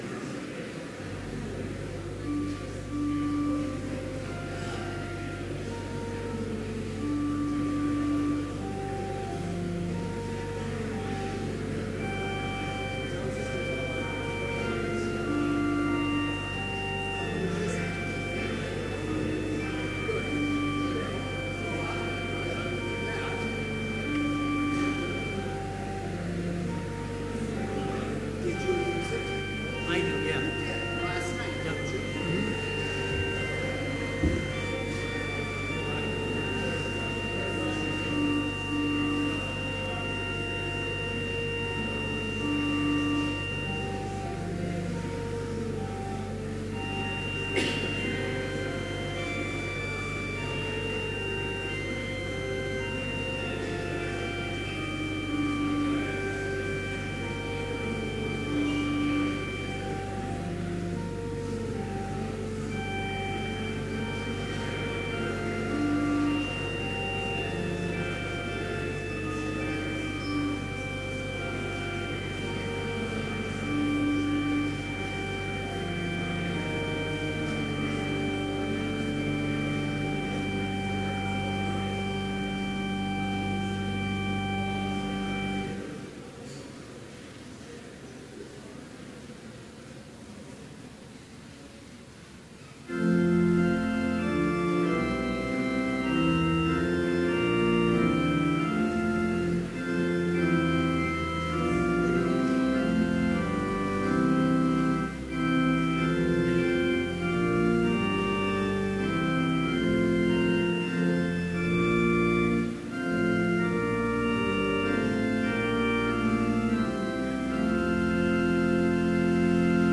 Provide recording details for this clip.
Complete service audio for Chapel - December 7, 2017